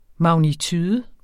Udtale [ mɑwniˈtyːðə ]